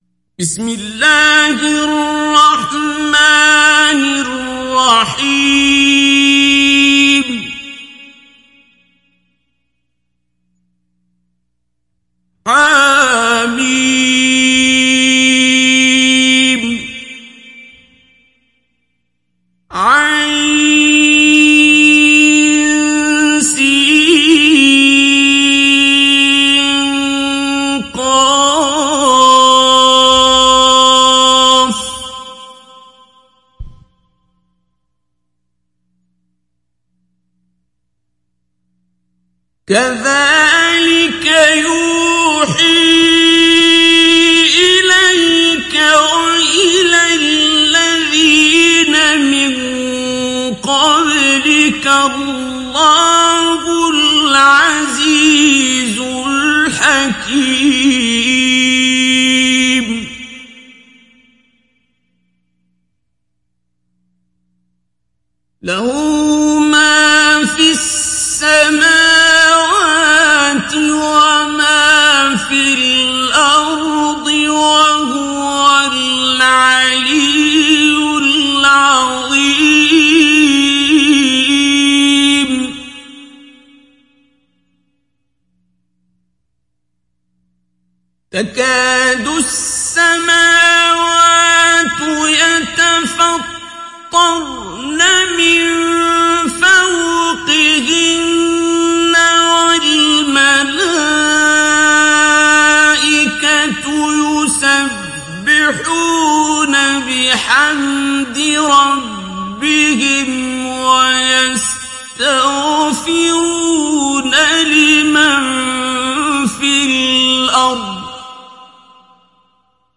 ডাউনলোড সূরা আশ-শূরা Abdul Basit Abd Alsamad Mujawwad